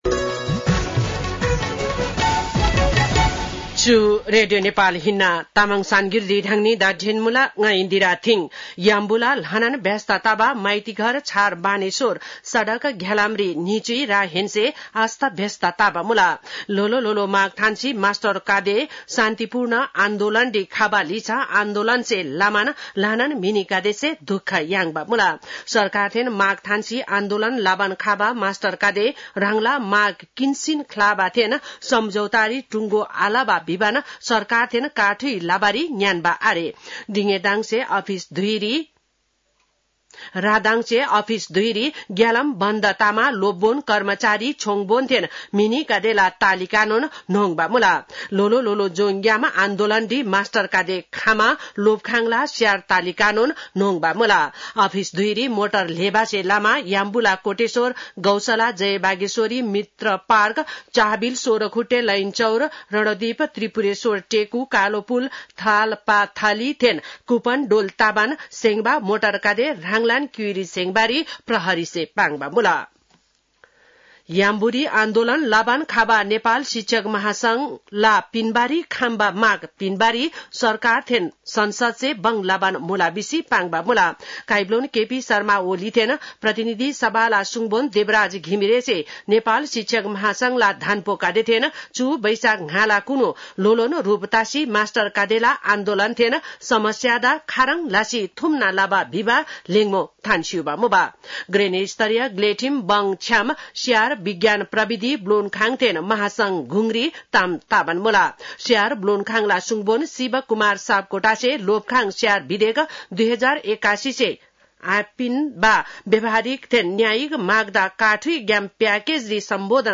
तामाङ भाषाको समाचार : ८ वैशाख , २०८२
5.5-pm-tamang-news.mp3